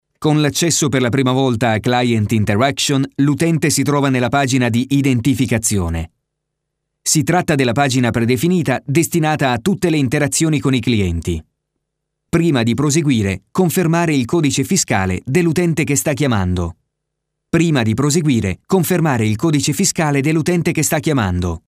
Voce giovane, adatta a comunicati dinamici, che richiedono un forte impatto energetico oppure per comunicati capaci di attirare l'attenzione con un messaggio chiaro, profondo e convincente.
Sprechprobe: eLearning (Muttersprache):
A new and fresh voice for your commercial, promos and any more!